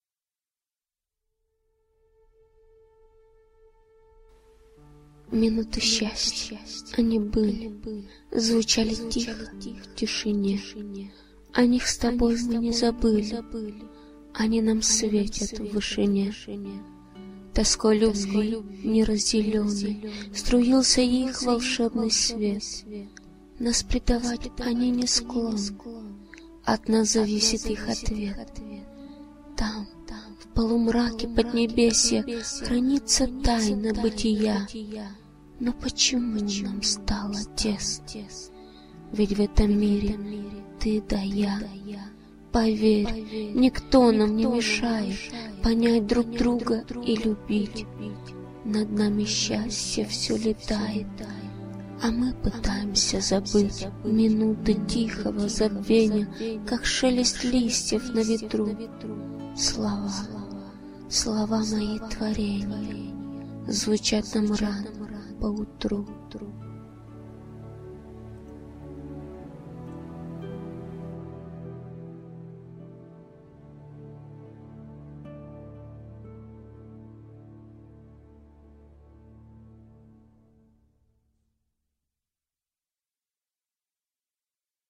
Чтение украшает далёкое эхо после каждой строчки.